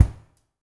LG KICK1  -R.wav